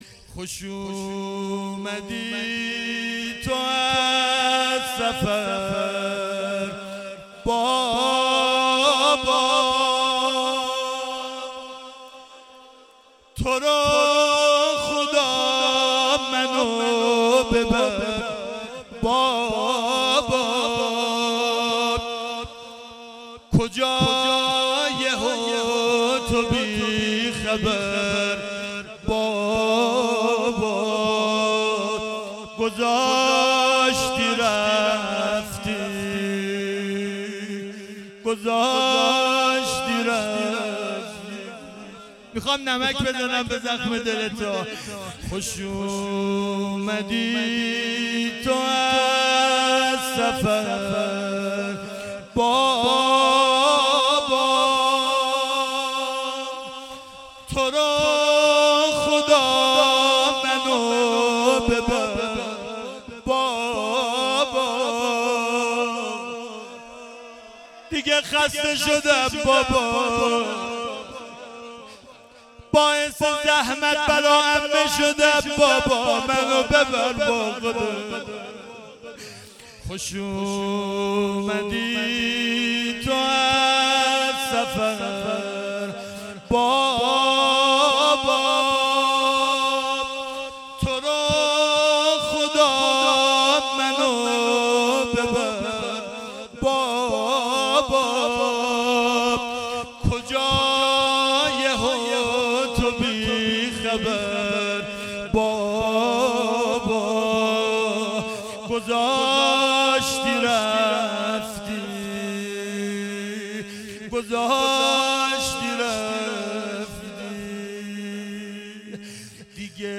خوش اومدی تو - روضه - شب بیست و سوم ماه مبارک رمضان 1396